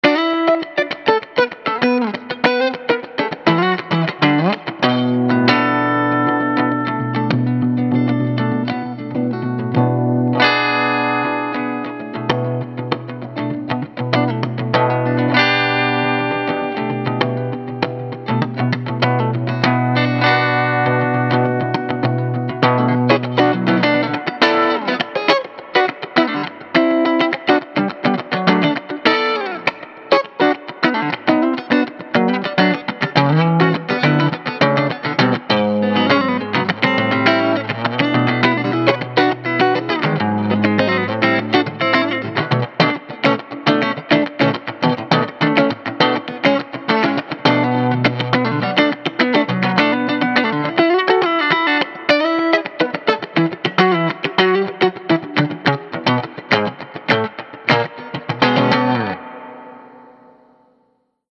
5W Class A - Single-Ended - 6V6 or 6L6 - Tube Rectified ~ ALL NEW Triode REVERB ~ 14lbs
Huggy Bear Slide • Tele • Clean   1:01